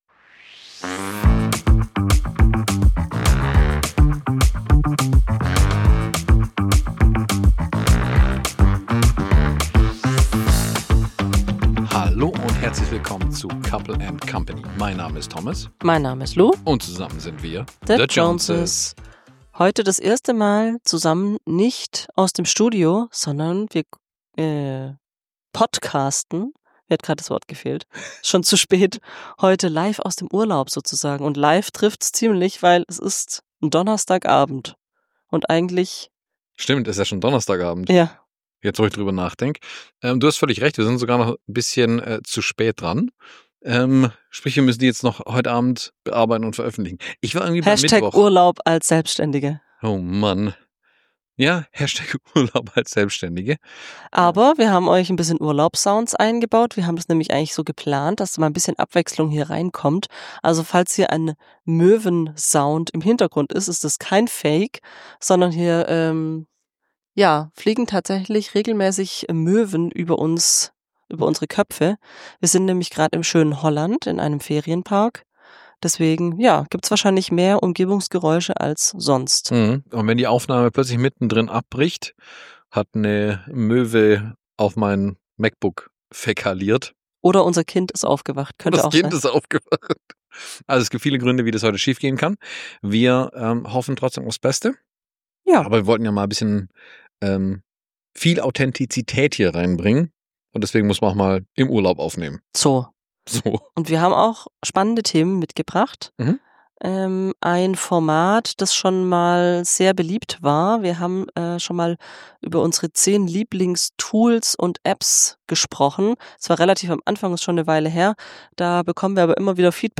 In dieser Folge nehmen wir euch mit an die holländische Küste! Während die Hintergrundgeräusche von Möwen und Meeresrauschen für Urlaubsfeeling sorgen, verraten wir euch, wie wir es schaffen, trotz Ferienmodus auch die Arbeit nicht aus dem Blick zu verlieren.